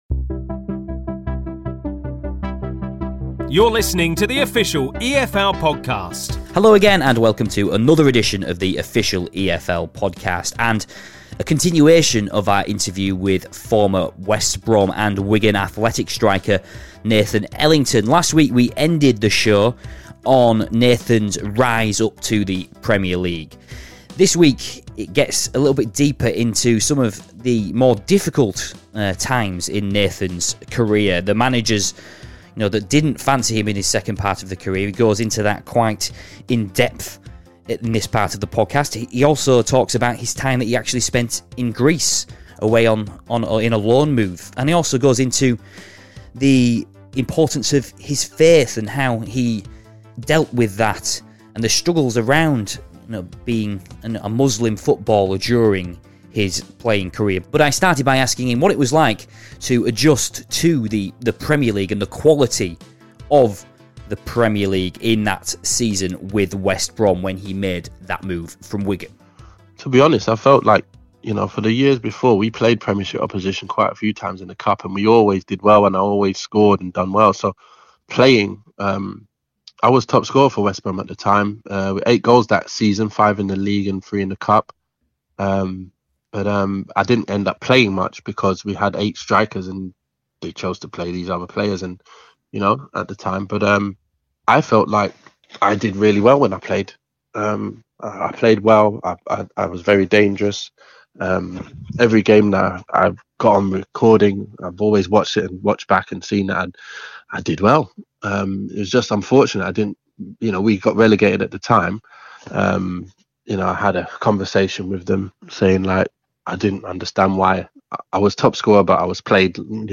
Part two of an extensive interview with former Wigan, West Brom, Watford and Bristol Rovers striker Nathan "The Duke" Ellington